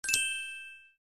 sfx_block_remove.wav